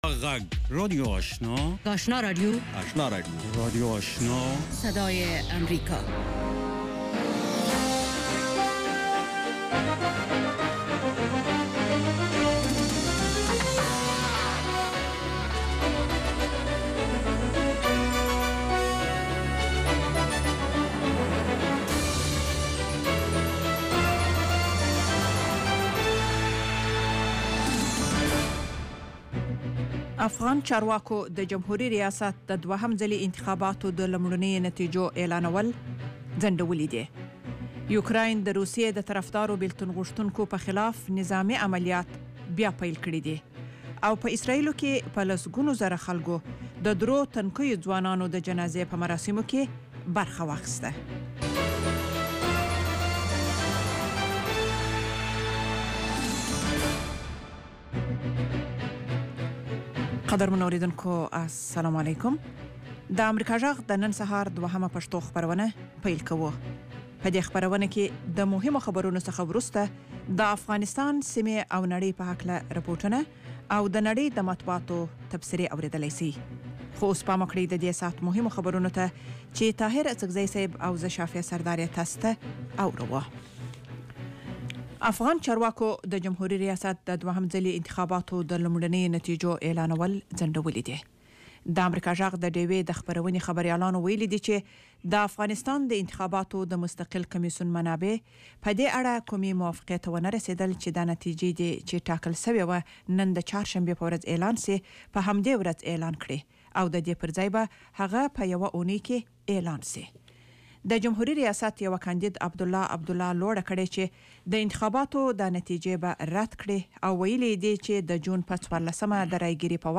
دویمه سهارنۍ خبري خپرونه